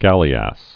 (gălē-ăs, -əs)